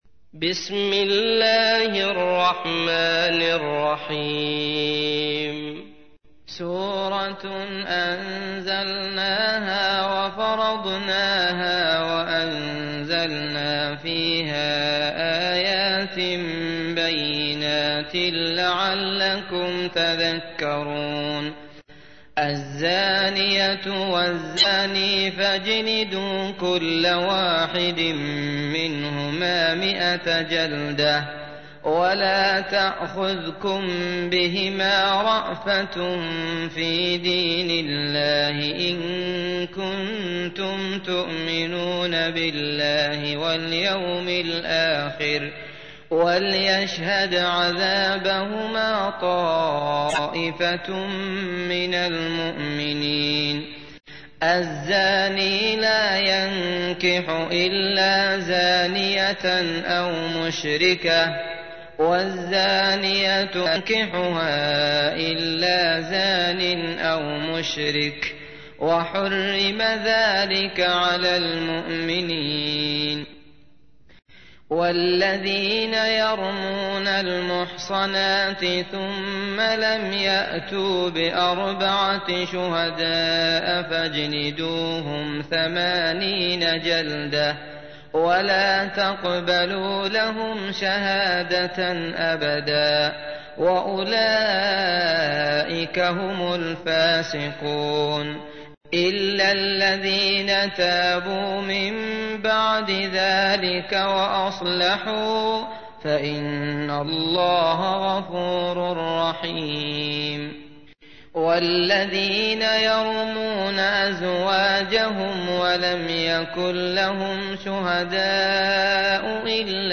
تحميل : 24. سورة النور / القارئ عبد الله المطرود / القرآن الكريم / موقع يا حسين